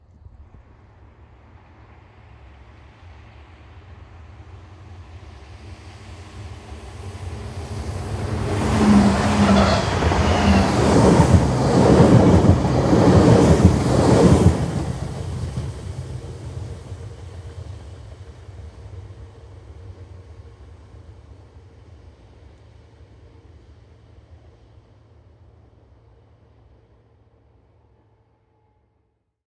火车 " 机车喇叭（口哨） " 机车广播
描述：固定式内燃机车爆炸喇叭。
Tag: 内燃机车 火车 火车喇叭 机车 铁路 柴油 火车汽笛声 发动机